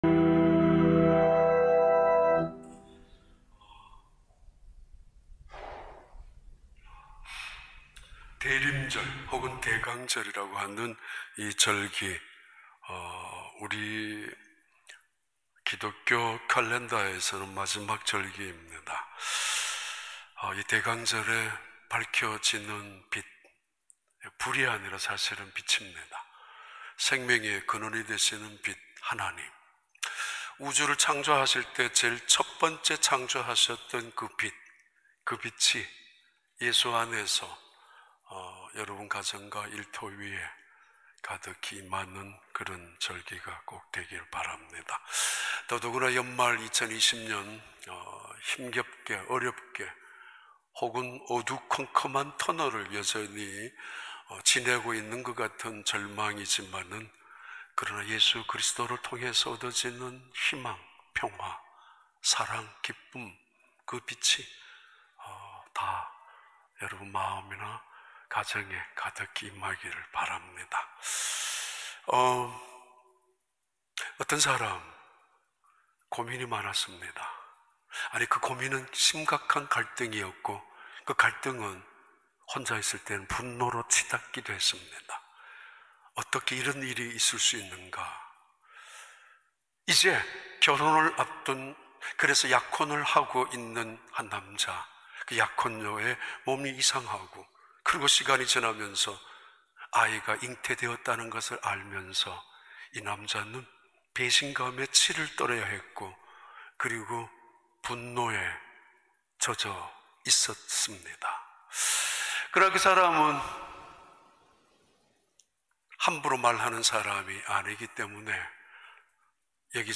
2020년 12월 20일 주일 4부 예배